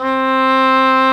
Index of /90_sSampleCDs/Roland L-CDX-03 Disk 1/WND_English Horn/WND_Eng Horn 2